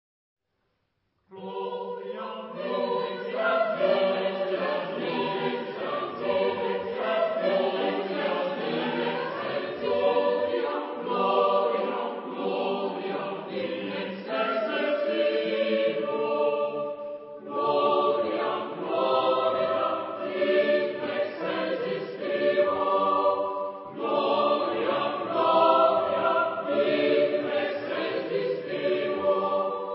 Epoque: 16 ; 17th century
Genre-Style-Form: Sacred
Type of Choir: SSATTB  (6 mixed voices )